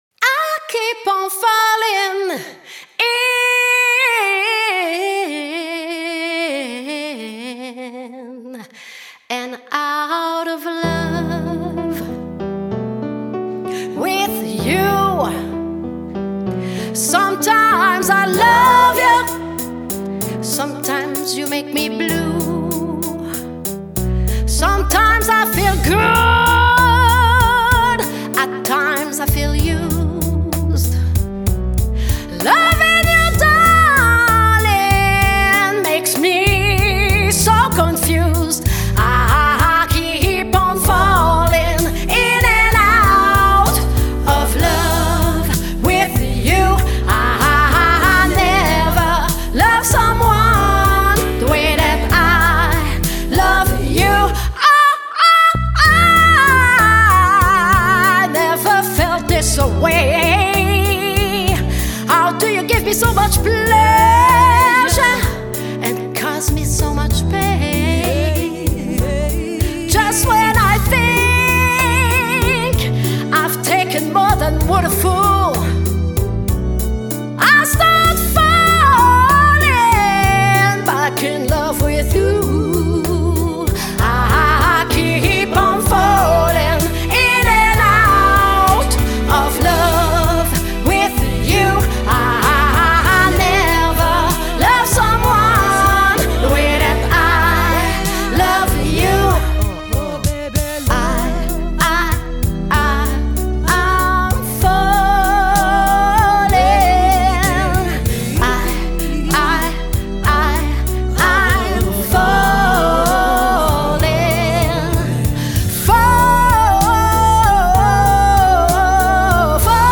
Soprano - Puissante
Chant 2025 3:21 6,4 Mo